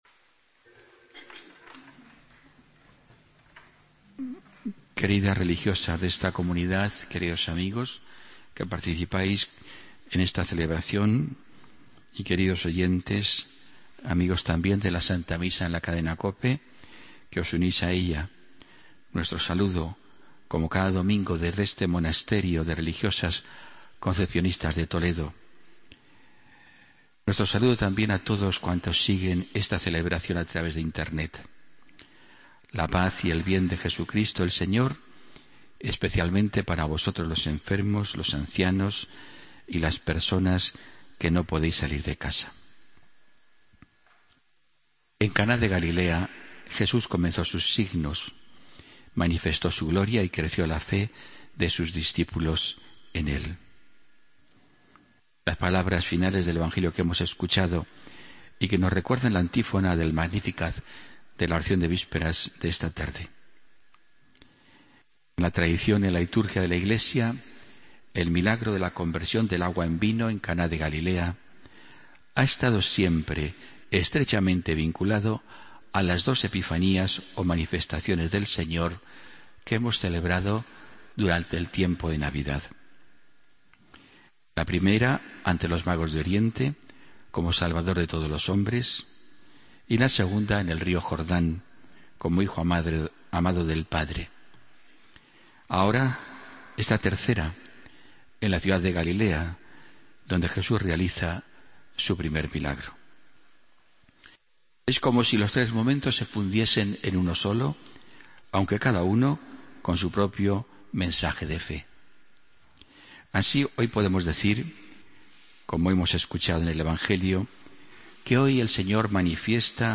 Homilía del domingo 17 de enero de 2016